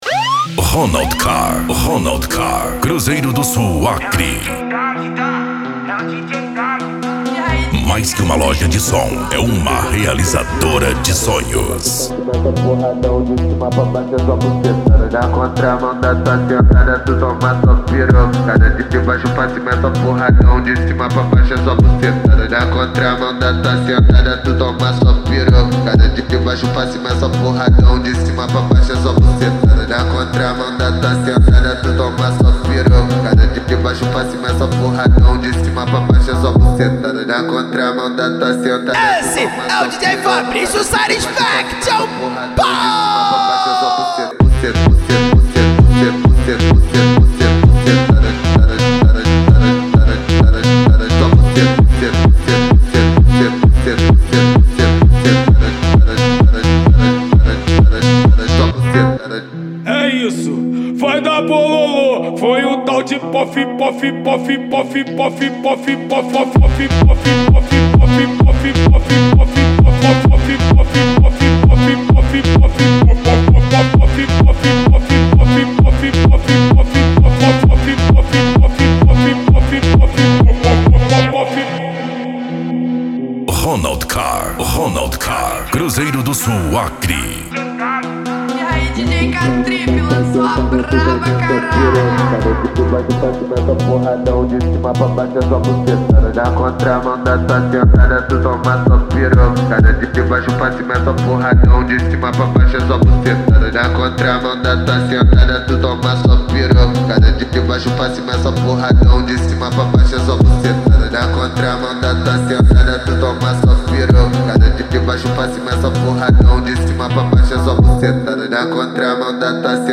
Funk
Musica Electronica